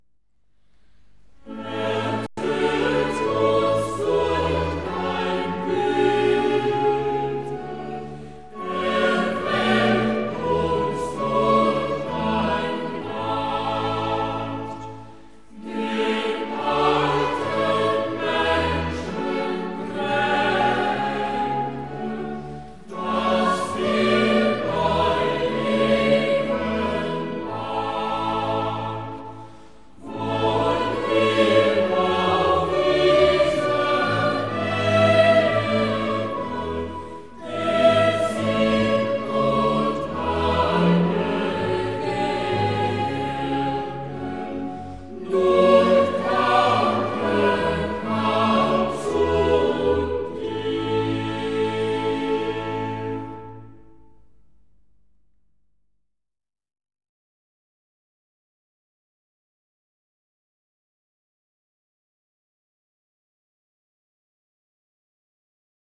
6. Choral
HautboisI/II et Violons I avec Soprani, Violons II avec Alti, Violes avec ténors, Continuo
06-6.-Choral-Ertot-uns-durch-dein-Gute.mp3